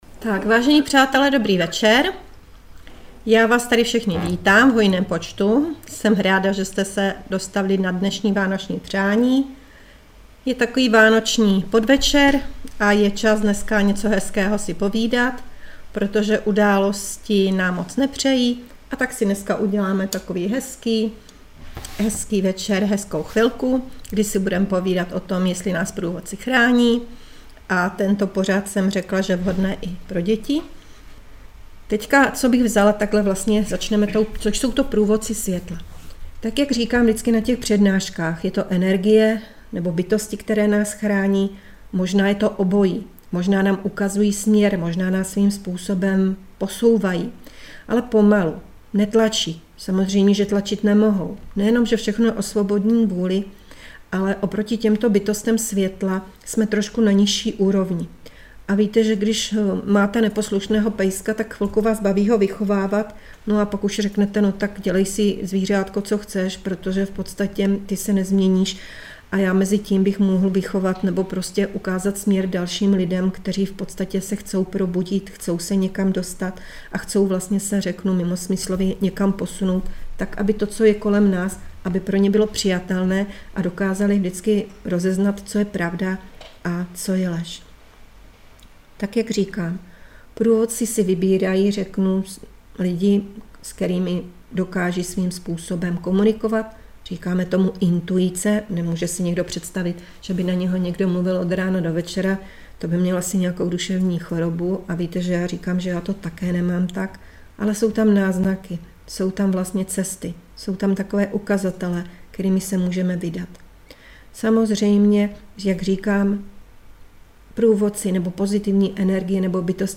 Tuto přednášku je možné přehrávat zdarma.